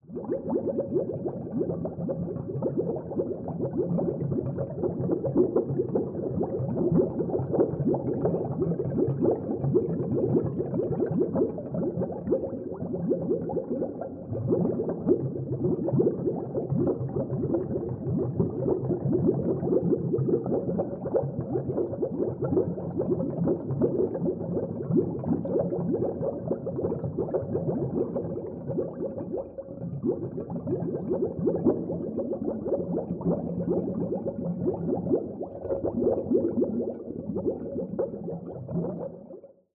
Dive Deep - Small Bubbles 07.wav